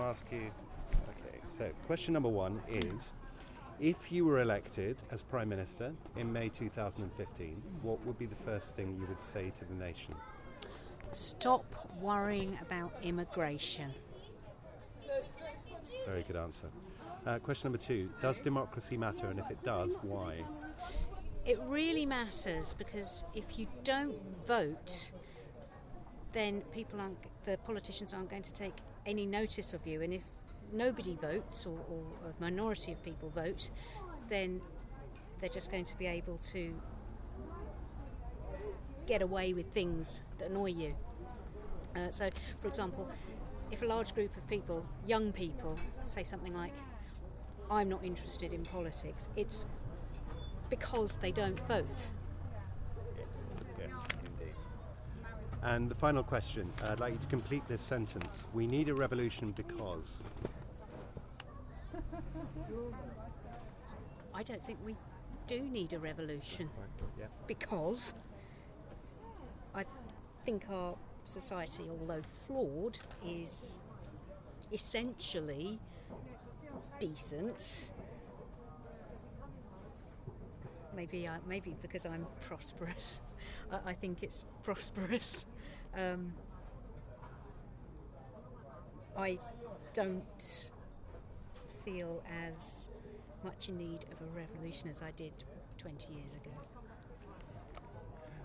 Interviews with the general public to answer three simple questions about democracy.Part of a public art project for InTRANSIT festival 2014, supported by Royal Borough of Kensington & Chelsea.